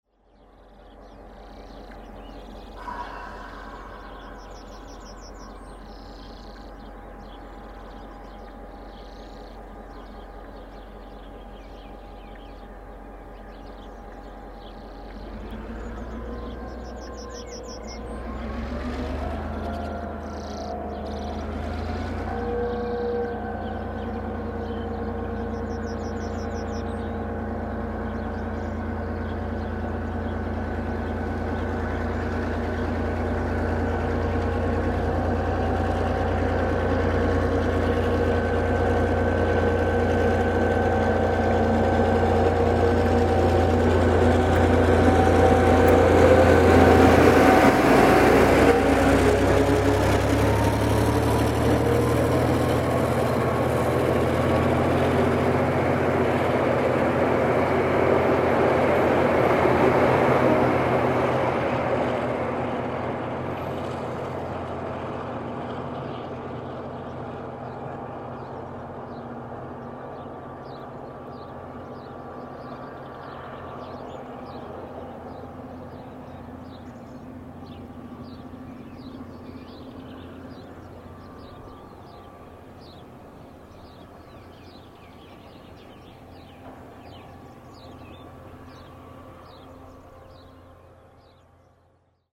The train stopped for a few minutes at Springfield, then headed off to tackle the climb up to Cass and Arthurs Pass.  Here is how it sounded leaving Springfield.